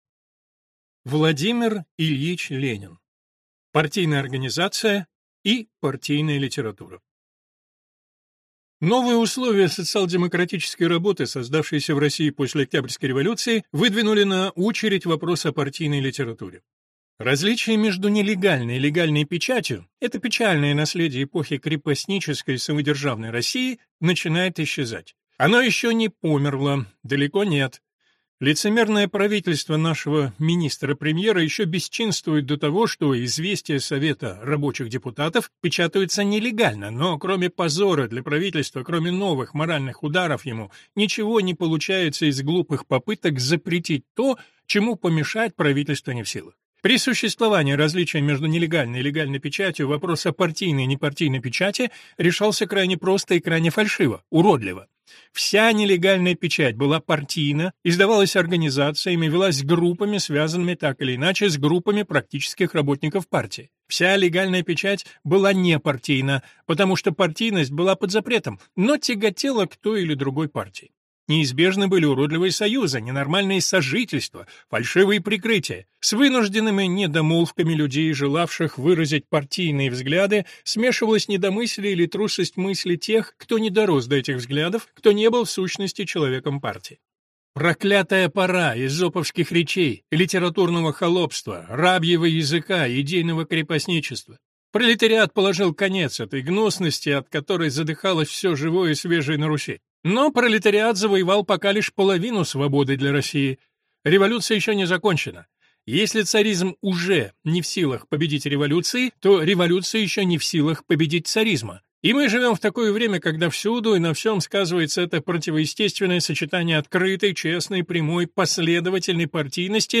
Аудиокнига Партийная организация и партийная литература | Библиотека аудиокниг